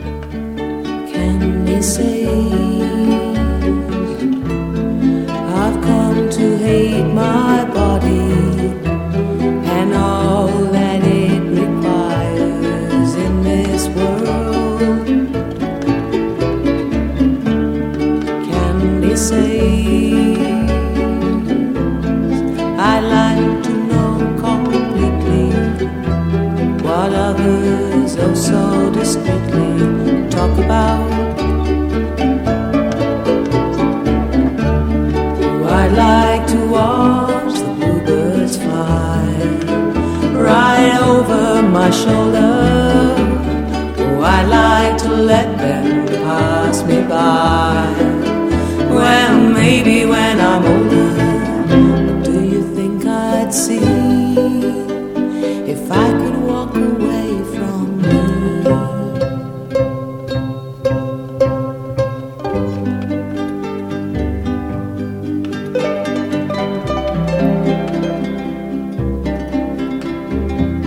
COW PUNK / HILLBILLY